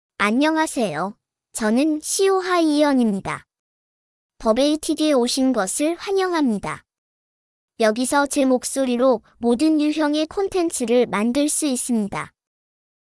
FemaleKorean (Korea)
SeoHyeon is a female AI voice for Korean (Korea).
Voice sample
SeoHyeon delivers clear pronunciation with authentic Korea Korean intonation, making your content sound professionally produced.